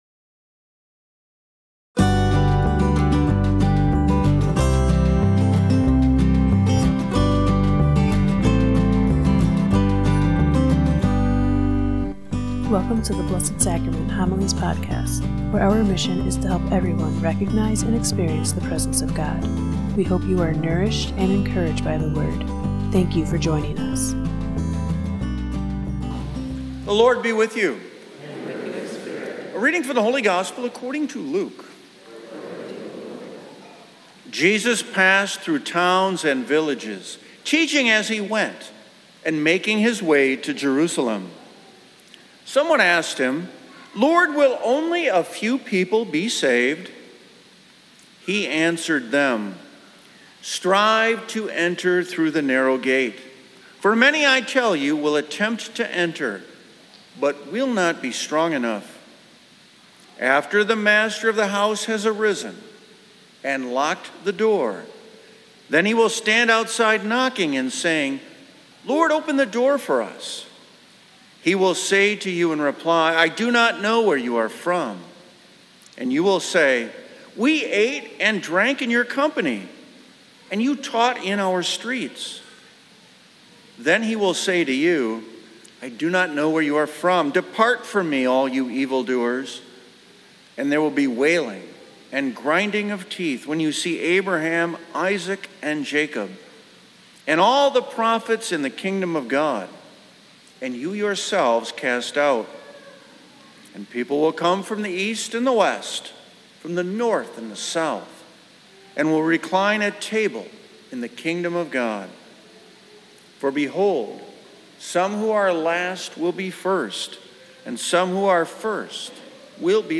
Blessed Sacrament Parish Community Homilies